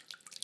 SplashCamera_04.ogg